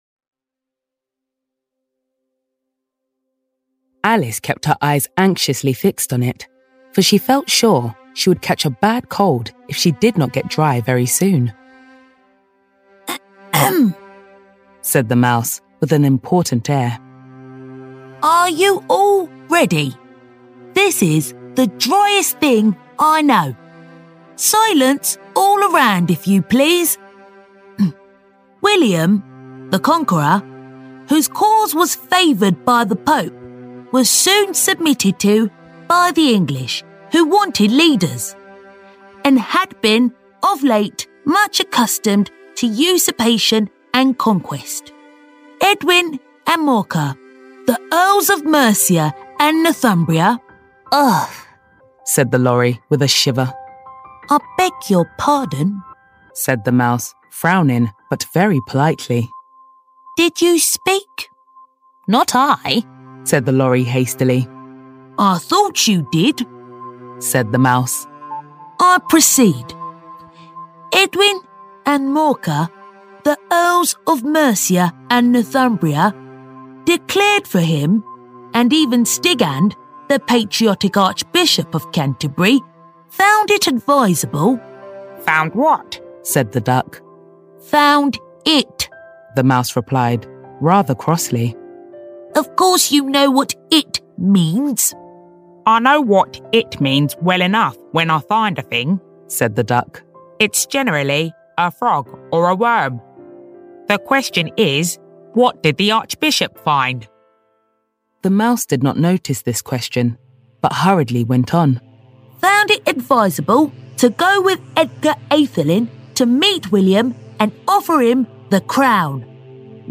Engels (Brits)
Warm, Commercieel, Diep, Vriendelijk, Zakelijk
Audiogids